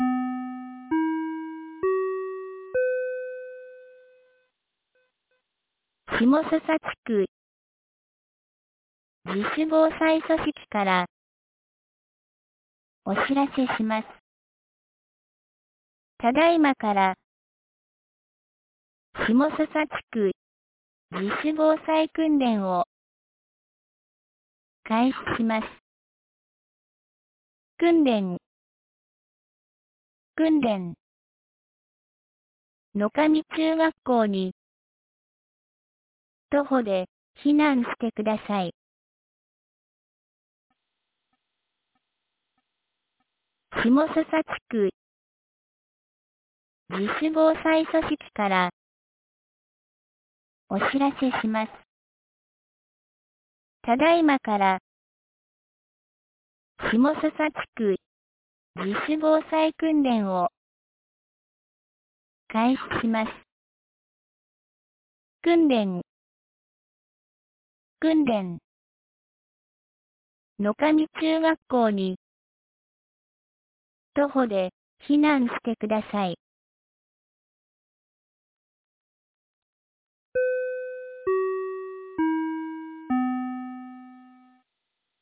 2023年11月12日 09時01分に、紀美野町より東野上地区へ放送がありました。